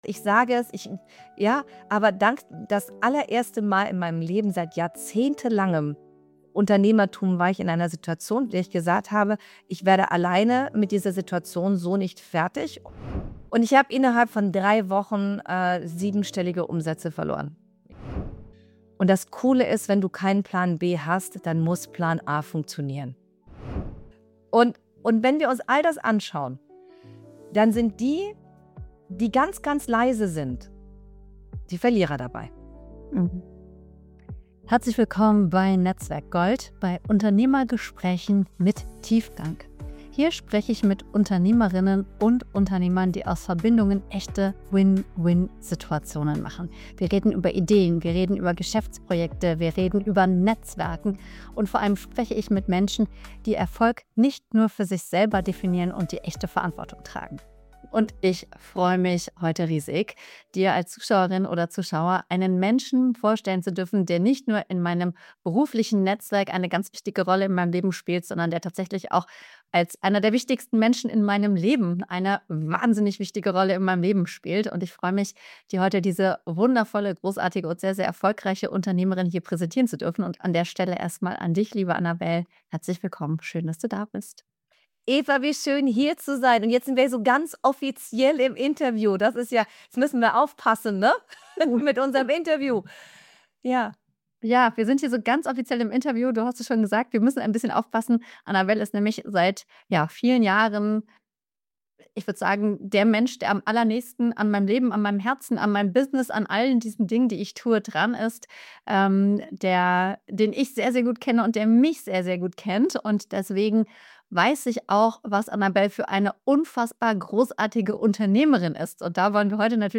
Willkommen beim NetzwerkGold Podcast – Unternehmergespräche mit Tiefgang. Hier triffst du Unternehmerinnen und Unternehmer, die groß denken und wertebasiert handeln.